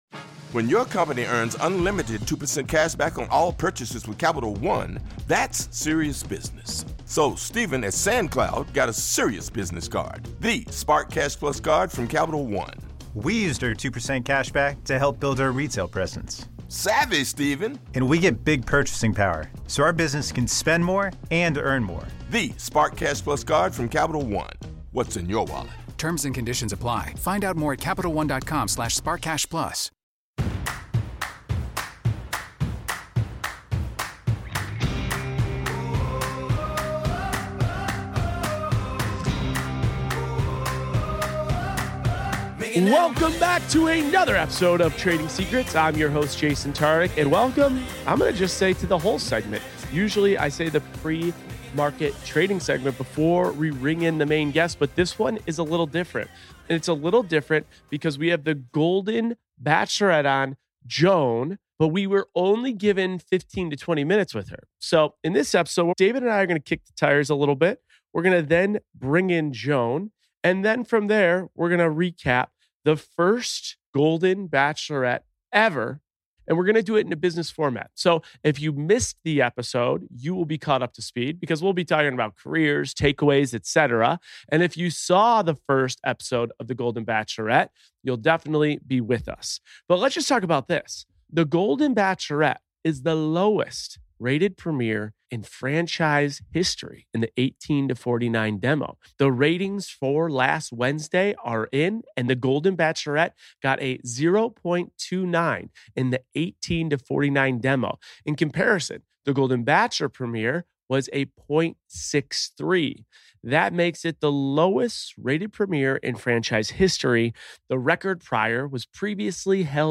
This week, Jason is joined by the Golden Bachelorette, Joan Vassos!
Host: Jason Tartick Co-Host
Guest: Joan Vassos